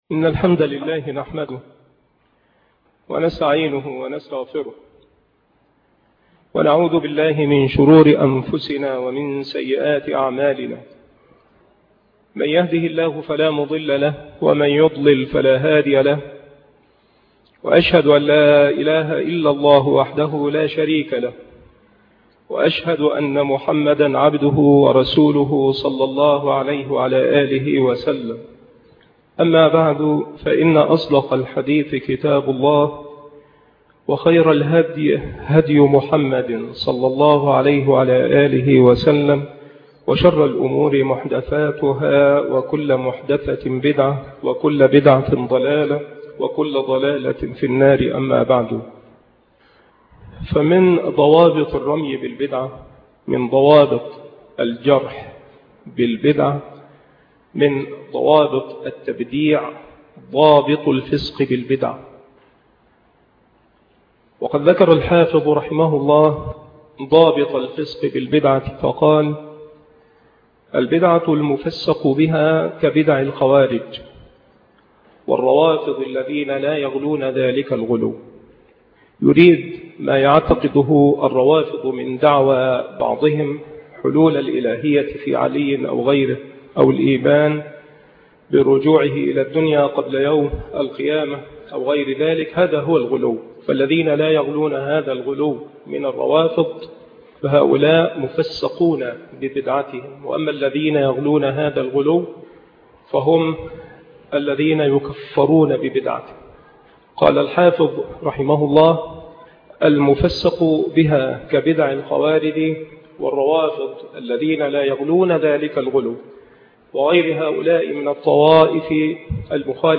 محاضرة ضابط